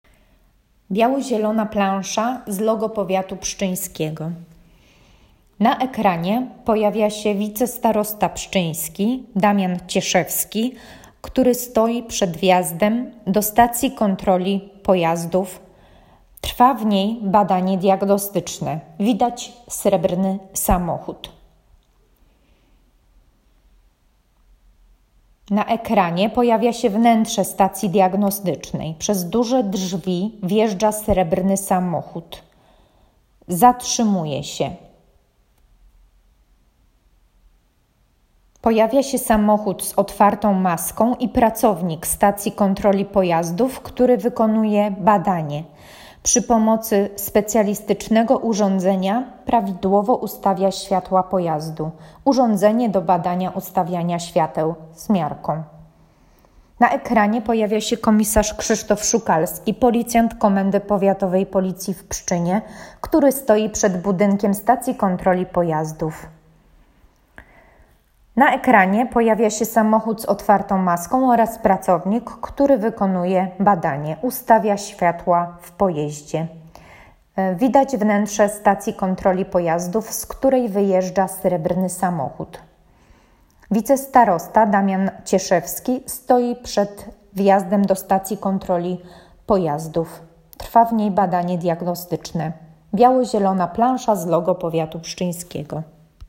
Dzięki dobrej współpracy Komendy Powiatowej Policji w Pszczynie i Powiatu Pszczyńskiego powstał spot zachęcający do udziału w akcji.